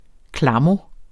Udtale [ ˈklɑmo ]